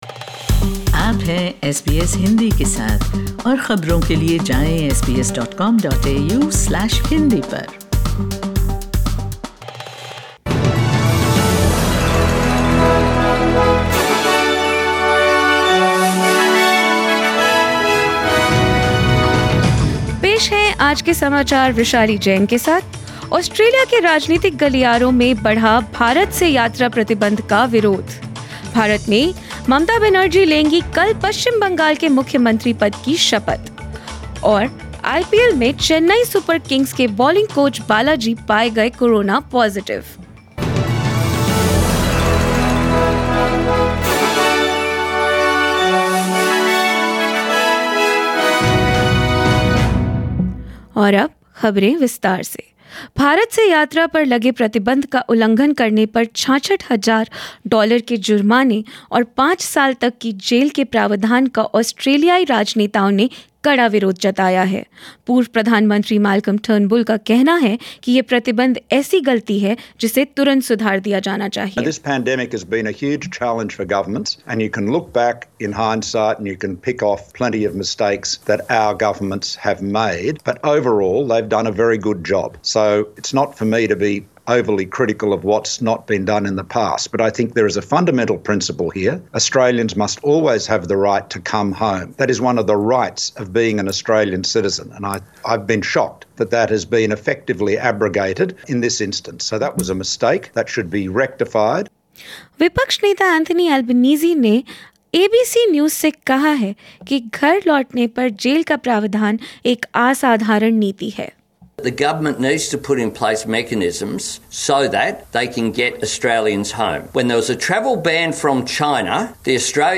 In this bulletin: Past and current Australian leaders have opposed the strict ban and penalties imposed on travel from India. Mamta Banerjee is set to take oath as the new West Bengal CM in a Covid-safe event.